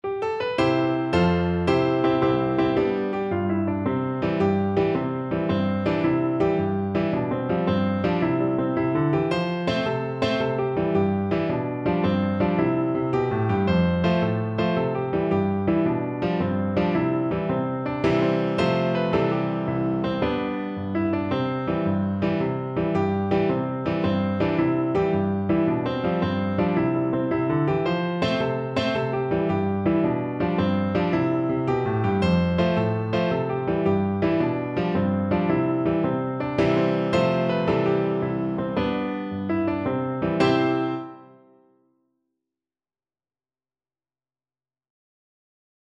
Piano version
No parts available for this pieces as it is for solo piano.
6/8 (View more 6/8 Music)
Allegro .=110 (View more music marked Allegro)
Piano  (View more Easy Piano Music)